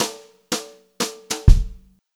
120JZFILL2-R.wav